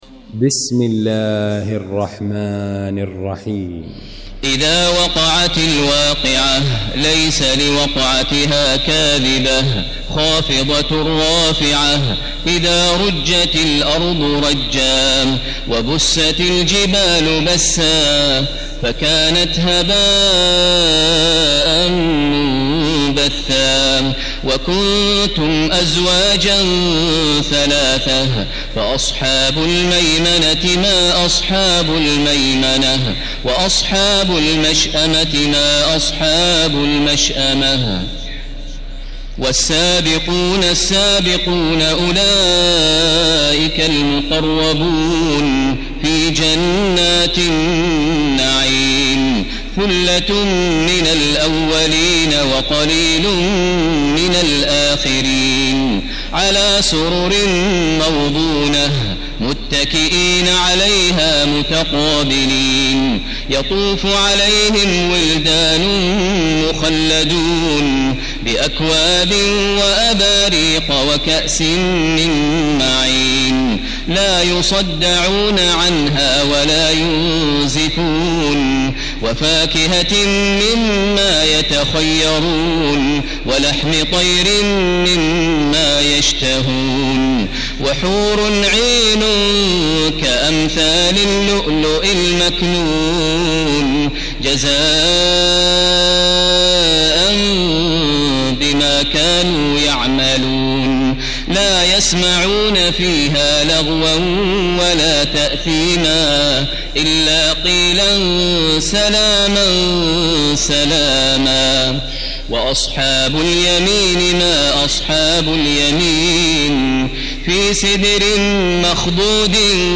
المكان: المسجد الحرام الشيخ: فضيلة الشيخ ماهر المعيقلي فضيلة الشيخ ماهر المعيقلي الواقعة